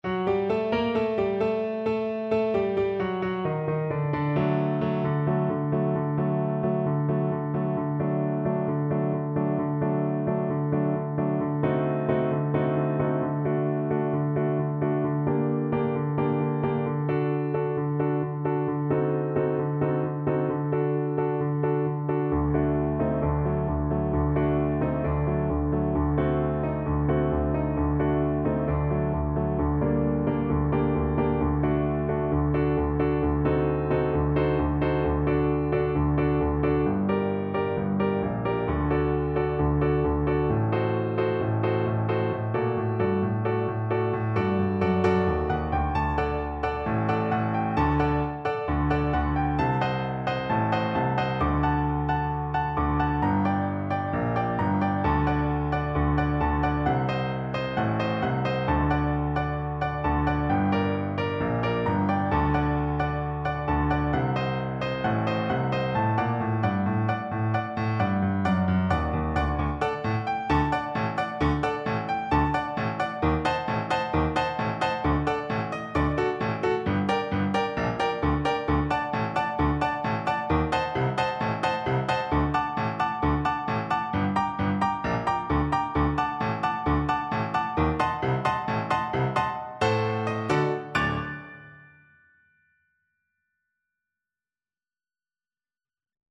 4/4 (View more 4/4 Music)
Molto allegro =c.132
Traditional (View more Traditional Viola Music)